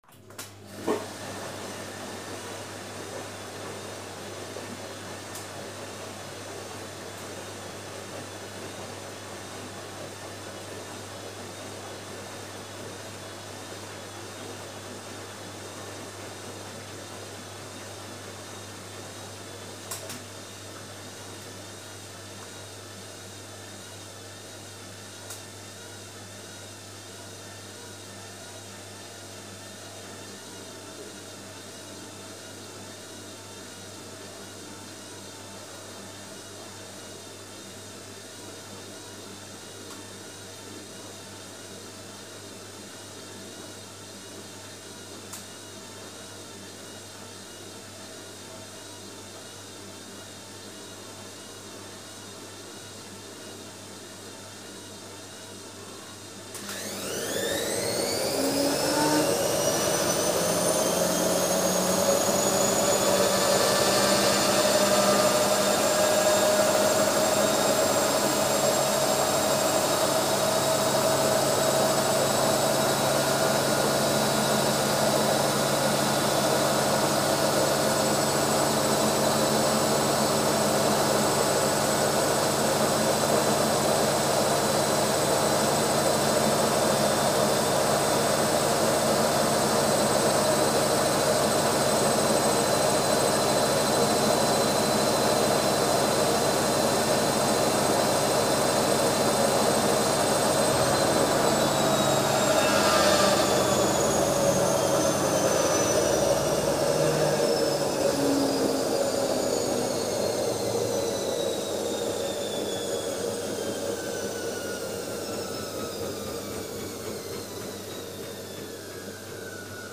Стиральная машина слив воды и отжим